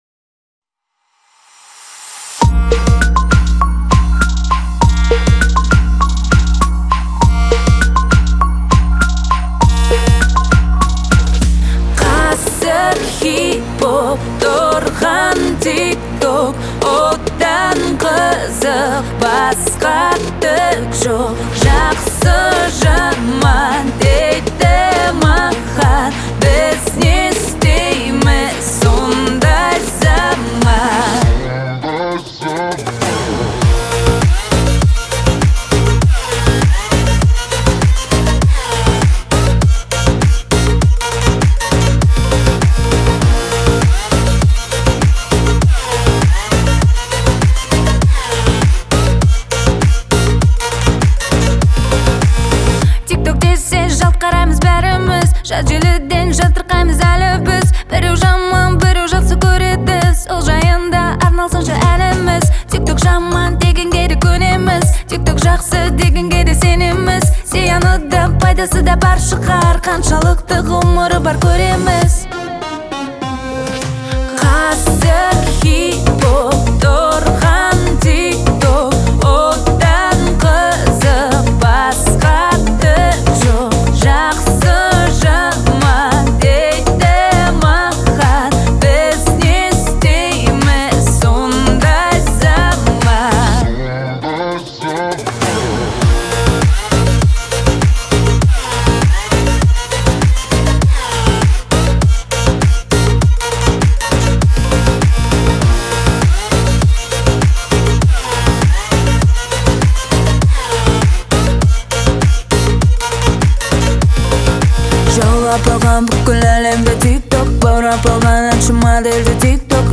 это яркий поп-трек, наполненный энергией и позитивом.